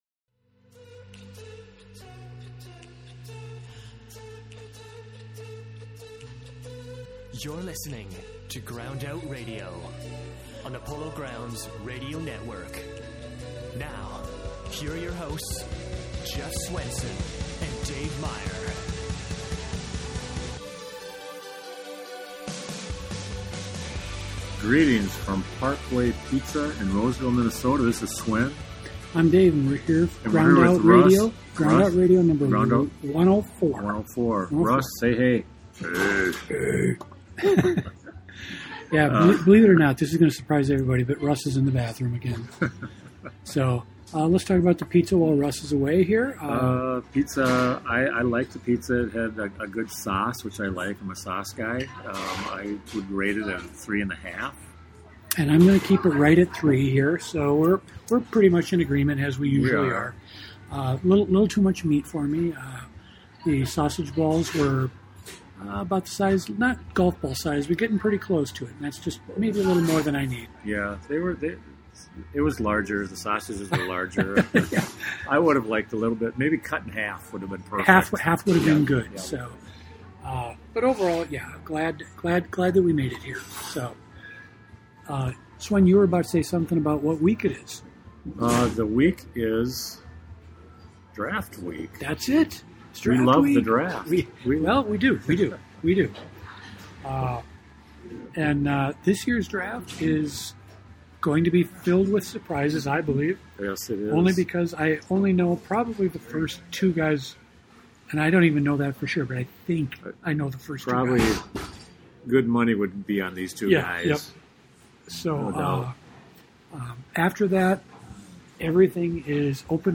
Recorded live from Parkway Pizza in St. Paul's Como-Lexington neighborhood, this podcast features plenty of draft nonsense and a few precosious predictions.